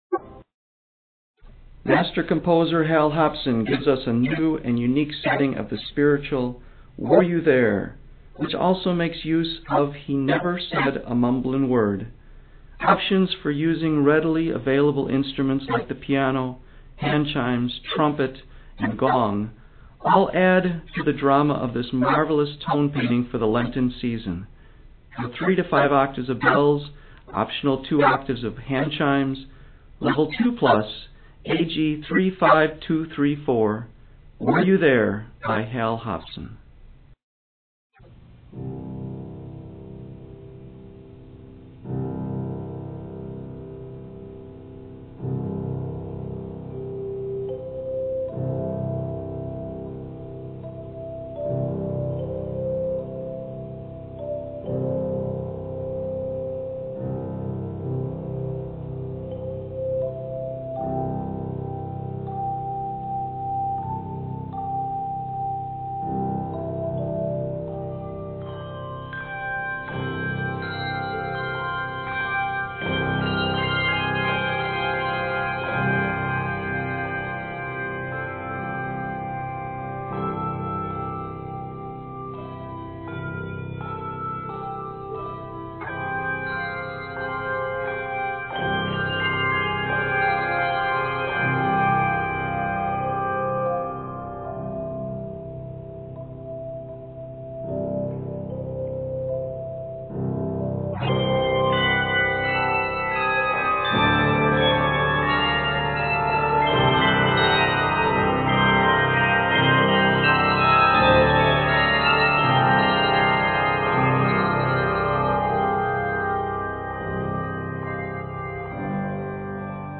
dramatic Lenten picture
spiritual